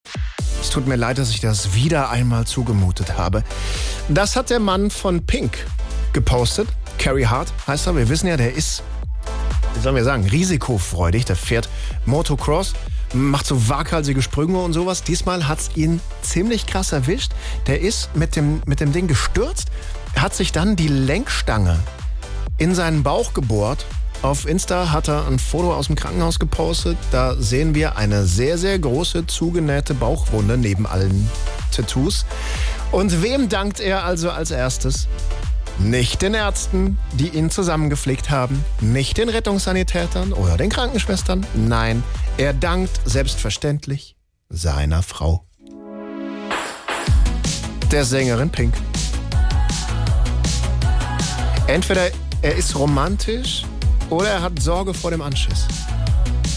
SWR3 Moderator
Nachrichten Nach dem Motorrad-Unfall: Carey Hart entschuldigt sich als erstes bei Pink